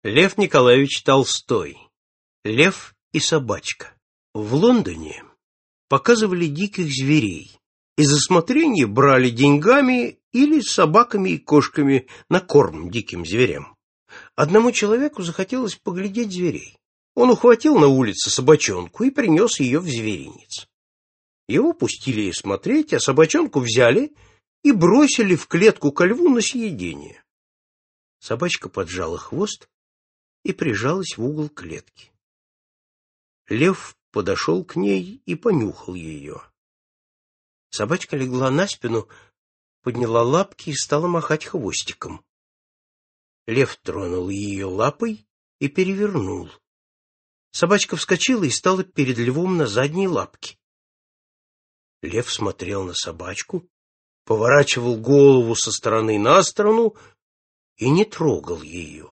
Аудиокнига Рассказы о животных | Библиотека аудиокниг